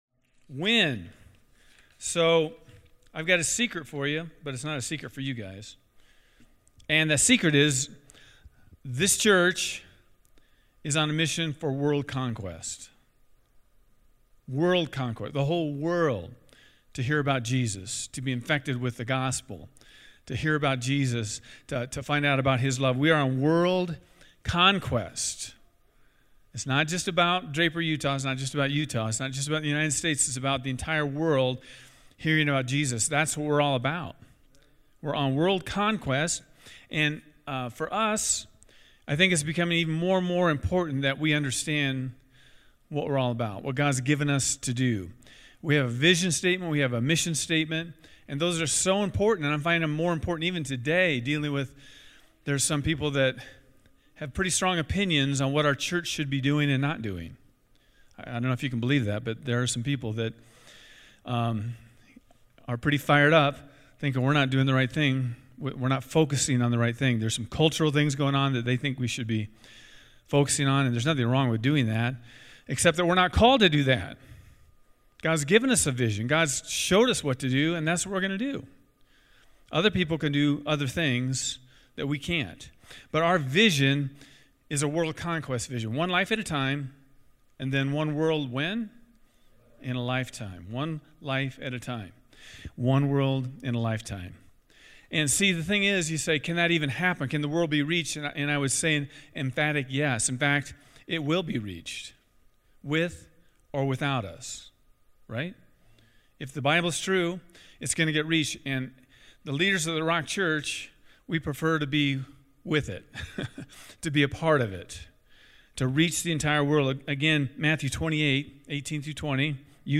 Win the lost. Build the believer. Send the sanctified. The pastors will be summarizing the vision for 2021.
These times are a monthly, co-ed discipleship meeting with worship, teaching, empowering, envisioning and fellowship — all with the leaders and aspiring leaders of our church.